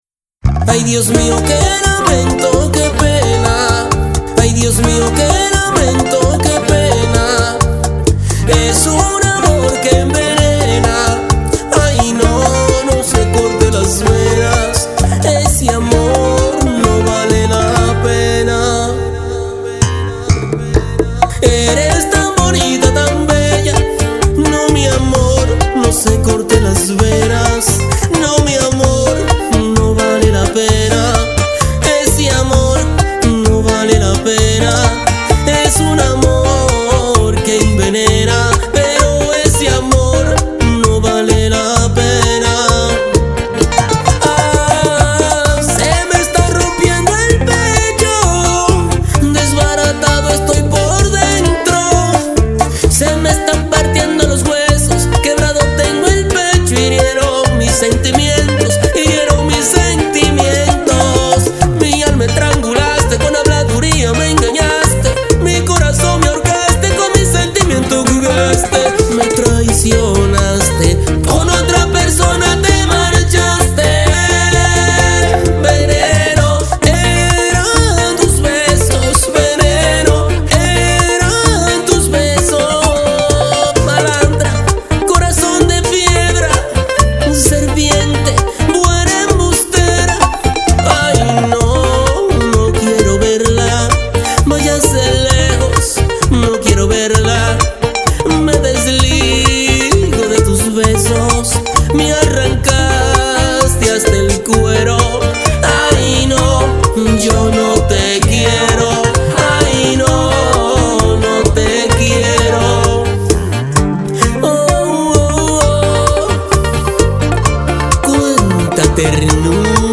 Bachata Poética Tropical